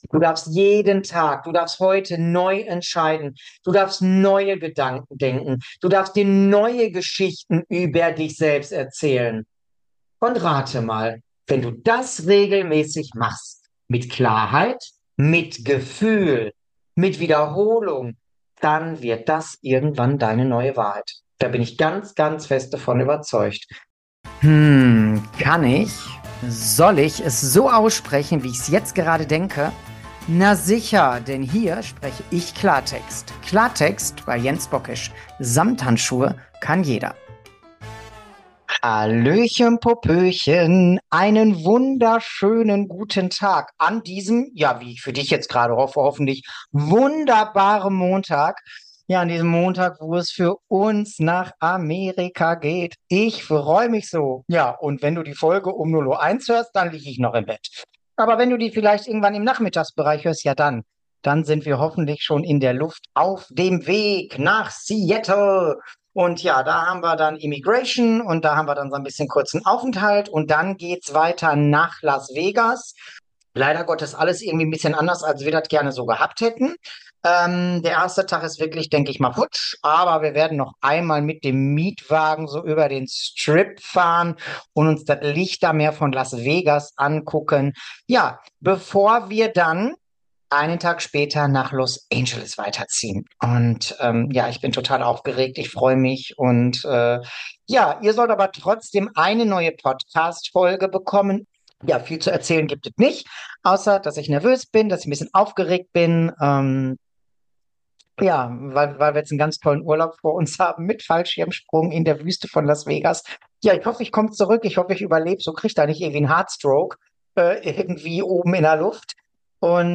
Ich zeige dir, wo diese Gedanken herkommen, wie sie dich sabotieren und vor allem, wie du sie Stück für Stück durch neue, stärkende Sätze ersetzen kannst. Ohne Schönreden, ohne Samthandschuhe – dafür mit Ehrlichkeit, Motivation und einem kräftigen Schuss Ruhrpott-Klartext.